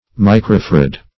Search Result for " microfarad" : Wordnet 3.0 NOUN (1) 1. a unit of capacitance equal to one millionth of a farad ; The Collaborative International Dictionary of English v.0.48: Microfarad \Mi`cro*far"ad\, n. [Micro- + farad.]
microfarad.mp3